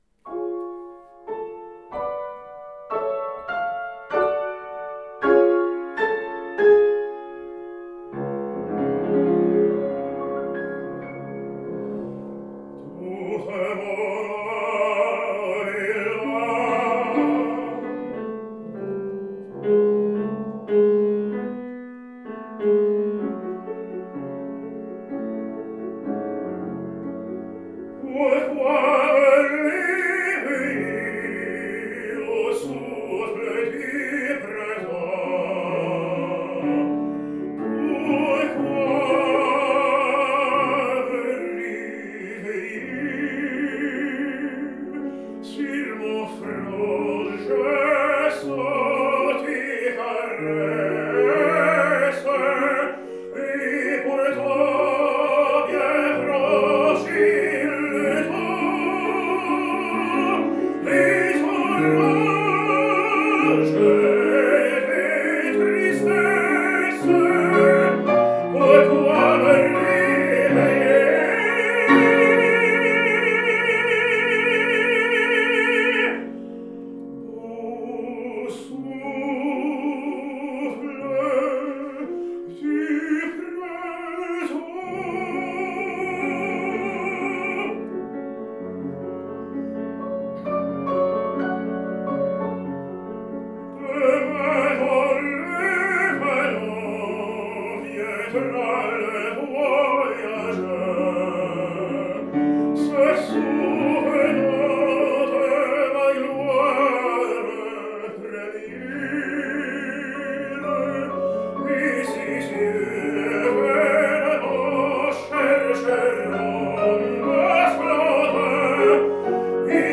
tenor 4:12 Das Preislied